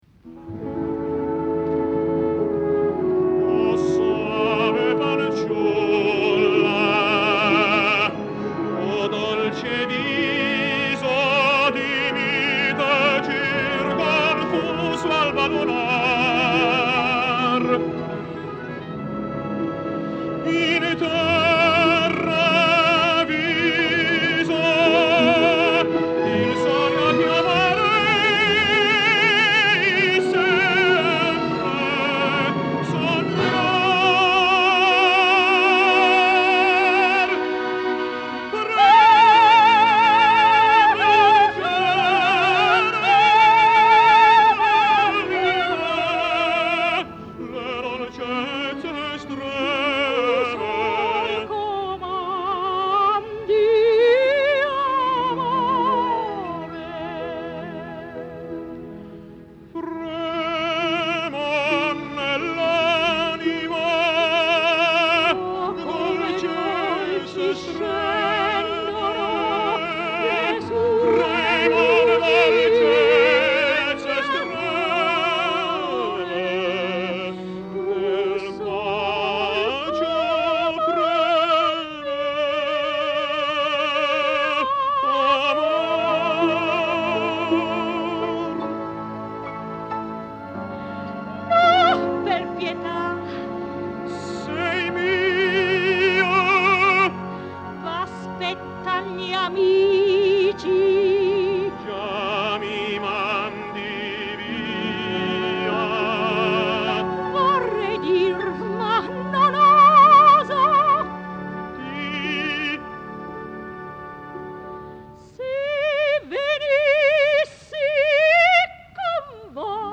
Lyssna här på en duett med Lycia Albanese och Jussi inspelad i San Francisco 23 oktober 1949 genom att klicka på länken: